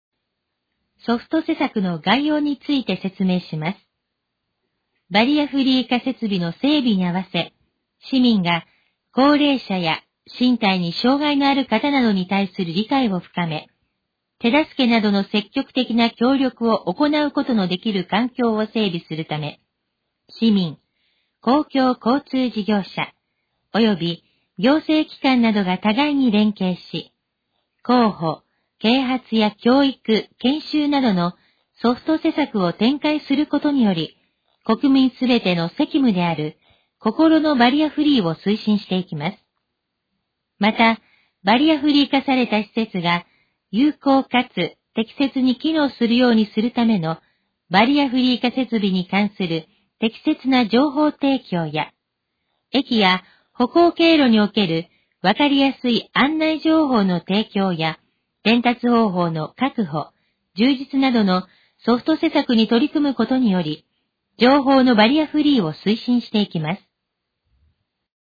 以下の項目の要約を音声で読み上げます。
ナレーション再生 約133KB